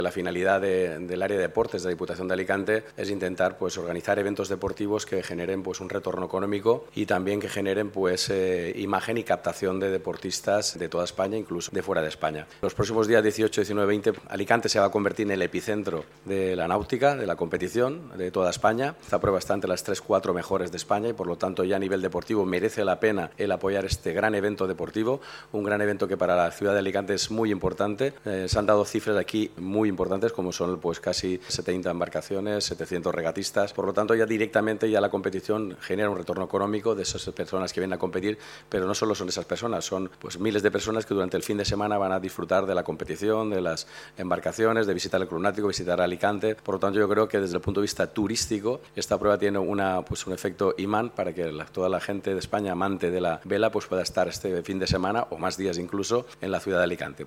Presentacion-Regata-Tabarca-Vela-Diputacion-CORTE-Bernabe-Cano.mp3